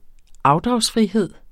Udtale [ ˈɑwdʁɑwsˌfʁiˌheðˀ ]